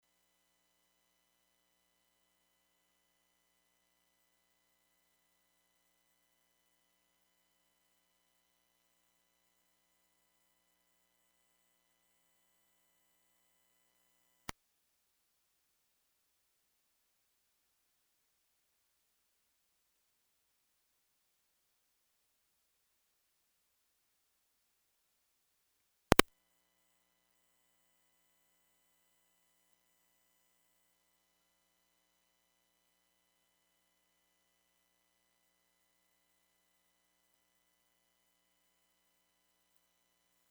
Low End Rumble in Mic
This is my preamp with the tube mic plugged into the power supply and preamp but without the mic turned on. Preamp is at max gain. View attachment 94009 You can hear at the 15 second mark I unplug the mic cable from the power supply, and things get quieter. Then I plug it back in and we hear the noise again.